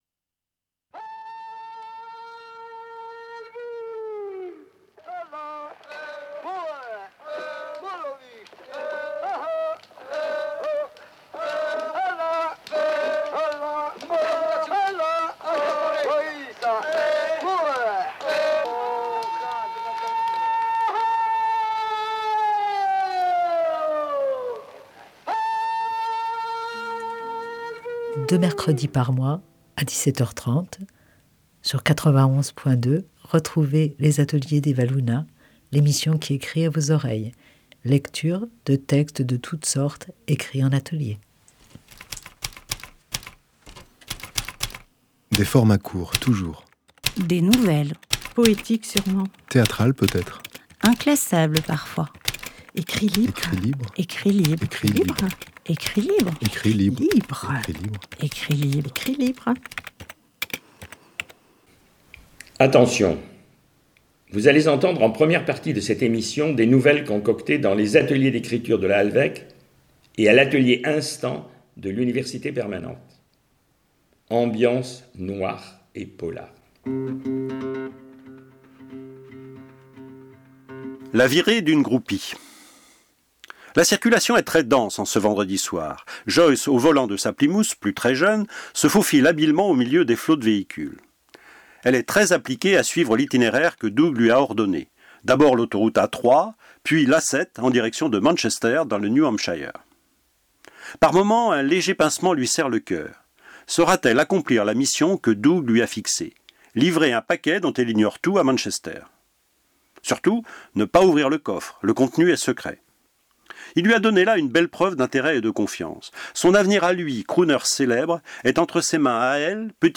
Durant cette émission lectures de nouvelles concoctées dans l’Atelier d’écriture de la Halvêque et dans l’atelier Instants de l’Université Permanente.